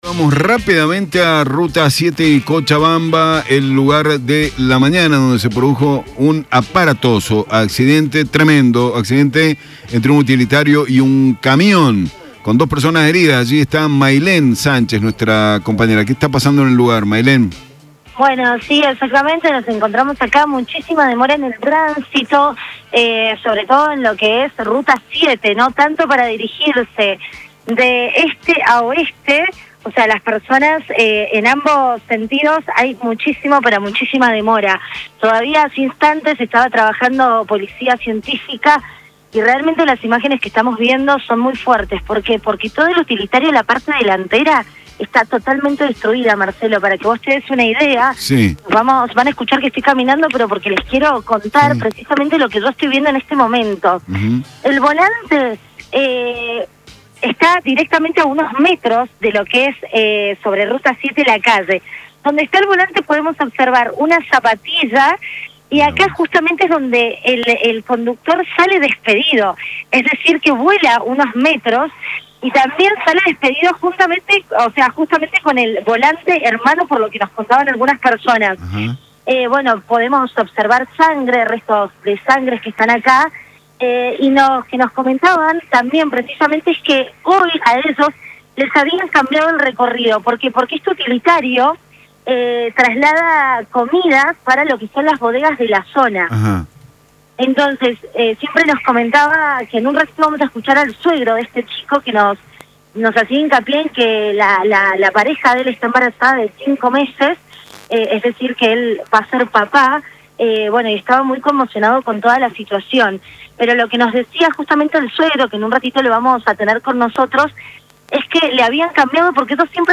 Móvil de LVDiez desde Agrelo, en Ruta 7 y Cochabamba, Lujan